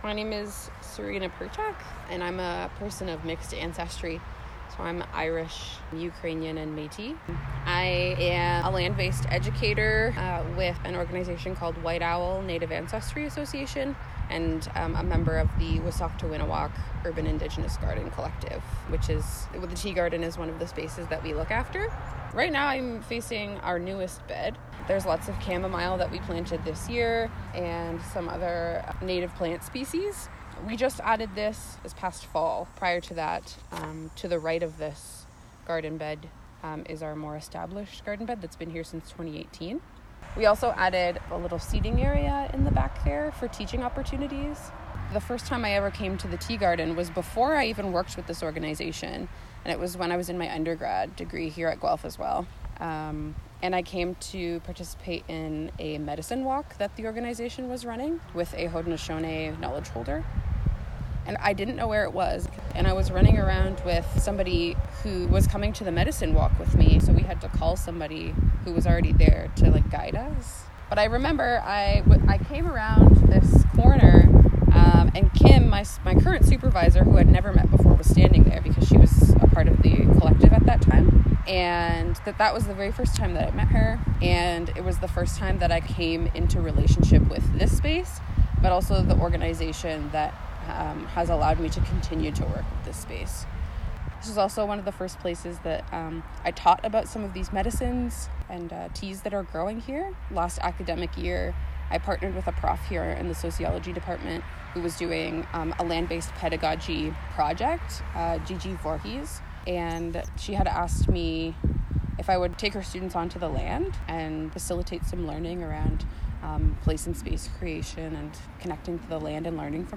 Interviewer
Interviewee